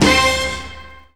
JAZZ STAB 3.wav